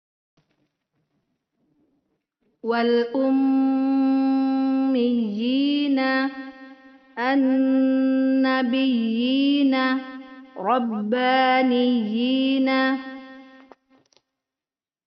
Panjangnya dibaca 2 harokat.